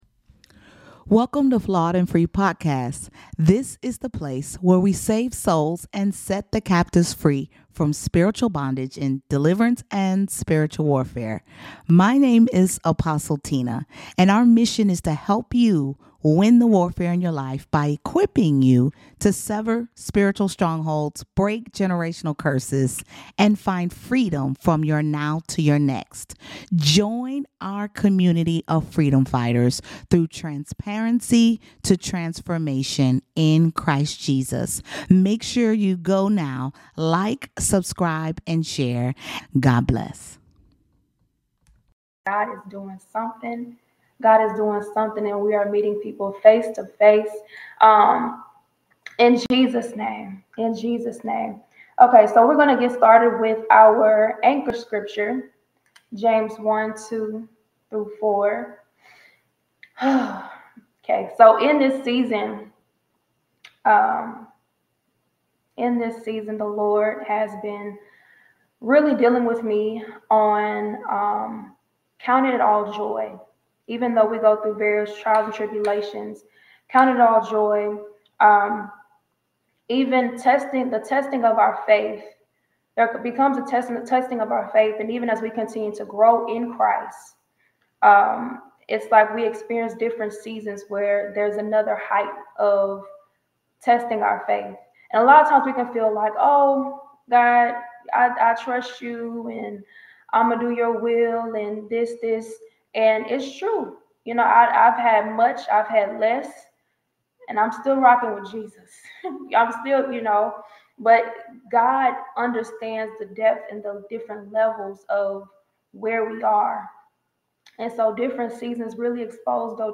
S5 Ep 197: Count It All Joy: The Need of Endurance | LIVE Sunday Service | Flawed & Free